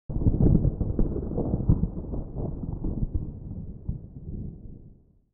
雷の音は低周波数が多め
p-sound-thunder.mp3